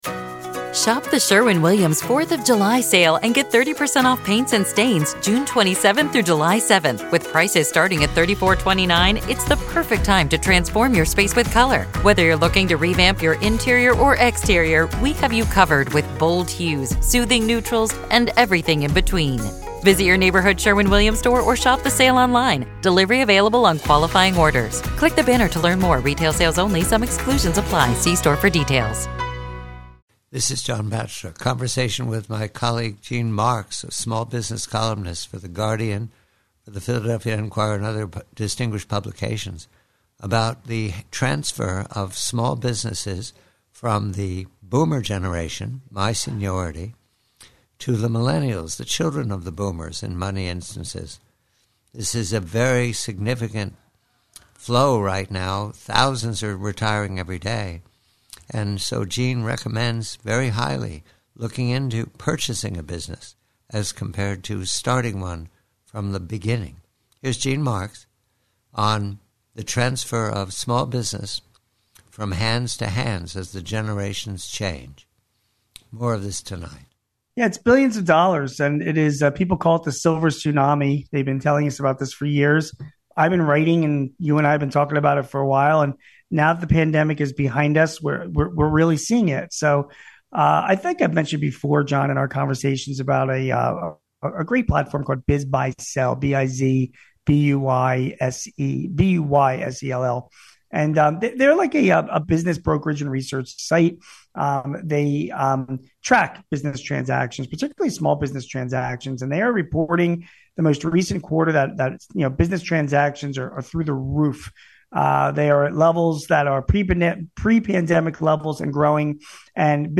PREVIEW: SMALL BUSINESS: BOOMERS